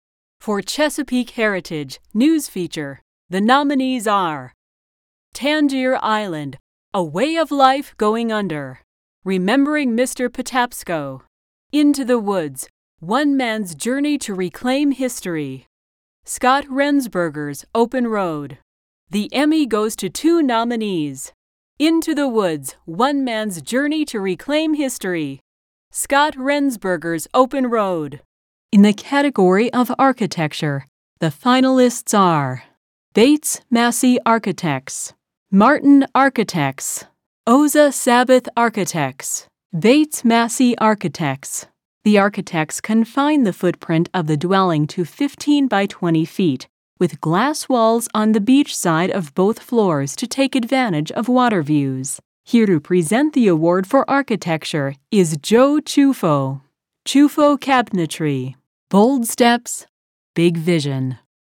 Professional, friendly yet no-nonsence, educated, natural female voice for narrations, eLearning, message-on-hold, commercials, award shows and website videos
Award Shows
English - Midwestern U.S. English